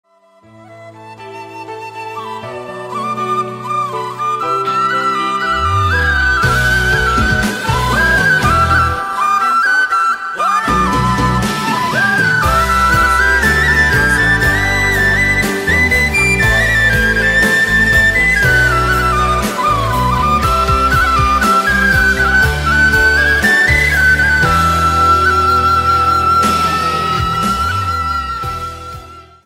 (Sáo Trúc) – Không Lời